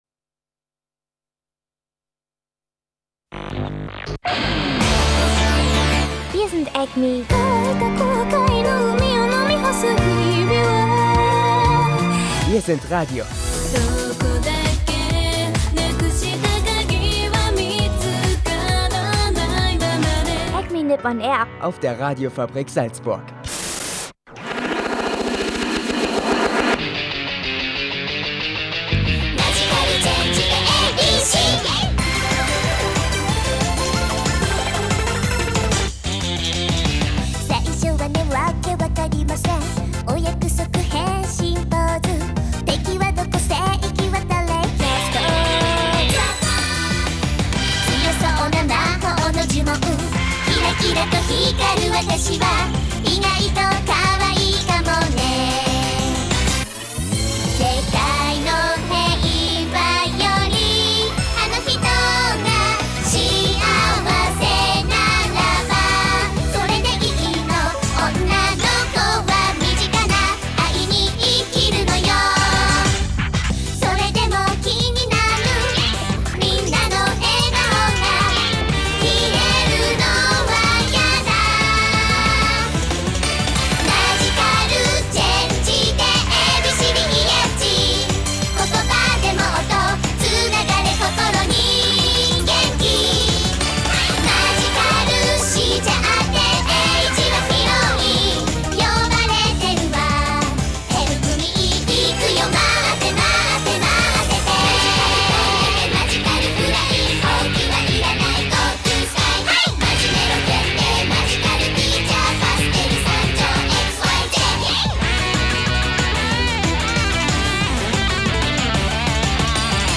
Sendung 198 diese Woche ohne übergreifendes Thema, dafür mit Anime-News zu Detektiv Conan und der Kyotoer Ubahn (ernsthaft), Japan-News zu Vulkanausbrüchen, Selbstmorden und Stromausfallursachen und viel Anime- und Japanmusik